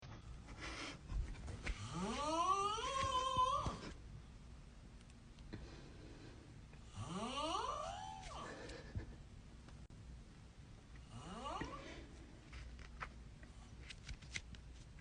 Memes
Snoring Guy